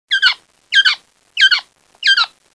鹌鹑的叫声特别的响亮，有人形容如滴水般的三音节哨音。
鹌鹑的叫声下载